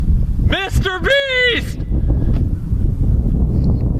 MrBeast Yelling Mrbeast